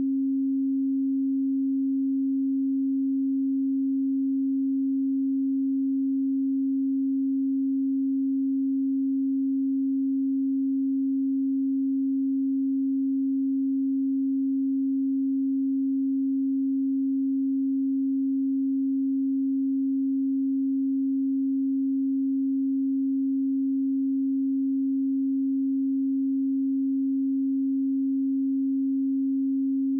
270Hz_-22.dB.wav